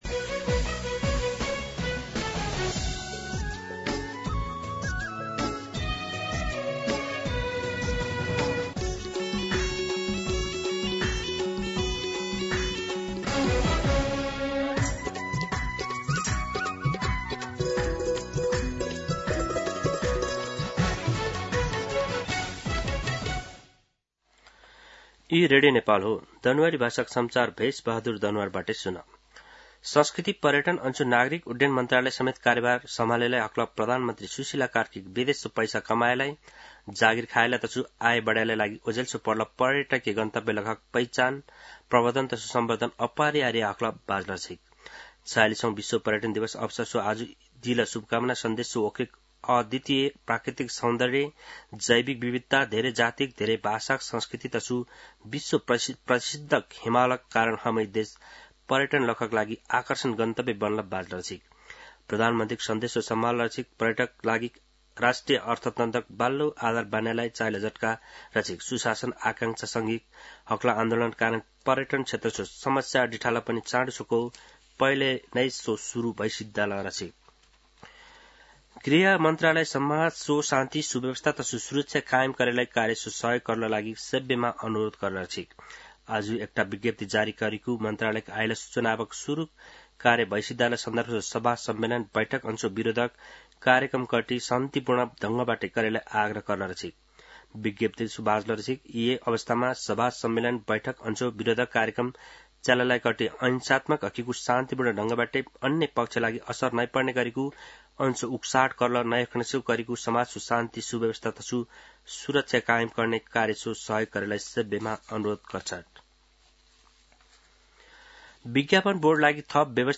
दनुवार भाषामा समाचार : ११ असोज , २०८२
Danuwar-News-8.mp3